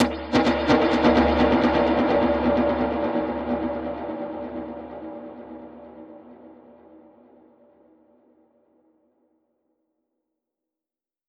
Index of /musicradar/dub-percussion-samples/85bpm
DPFX_PercHit_D_85-02.wav